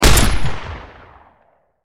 wpn_10mmpistolfire_3d_03.wav